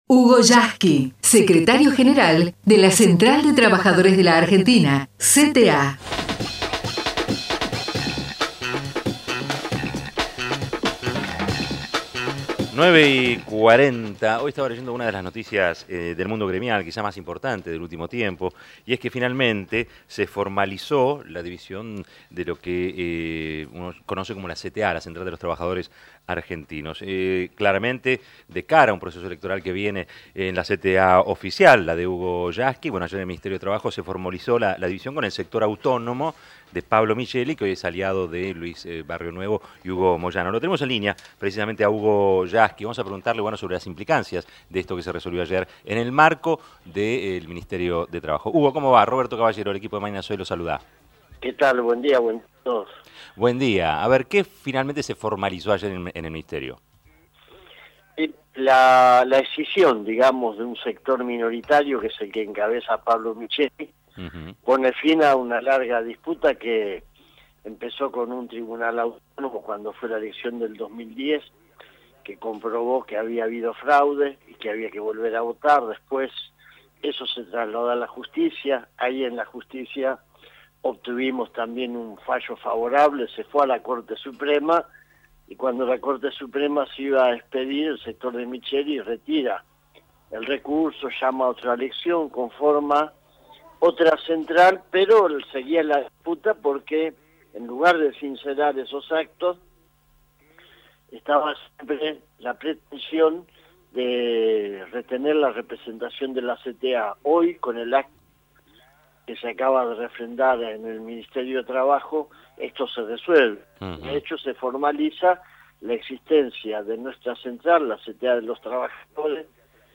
HUGO YASKY (entrevista) RADIO NACIONAL